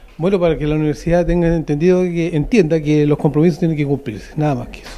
Uno de los trabajadores, tras emitir su voto, afirmó que la institución debe cumplir los compromisos adquiridos.